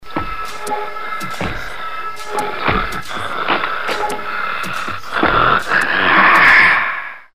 efectos-sonidos-cine-terror-gritos.mp3